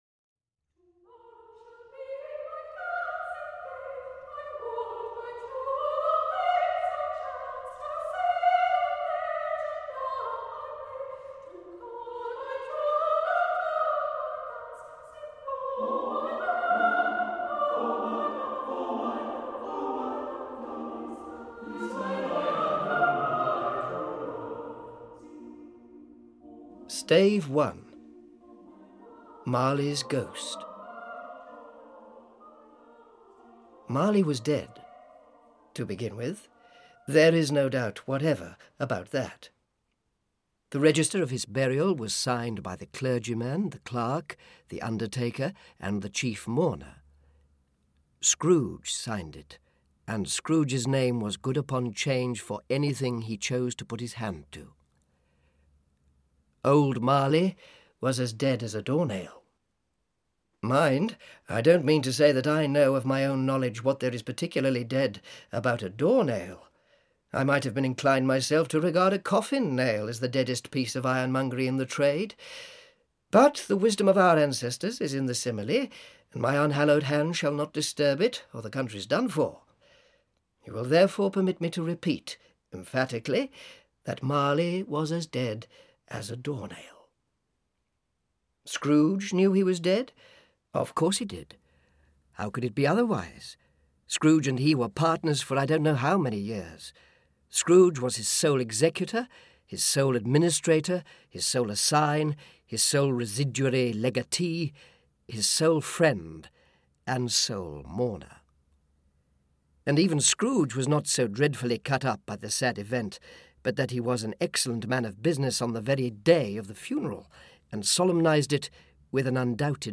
A Christmas Carol (EN) audiokniha
Ukázka z knihy
• InterpretAnton Lesser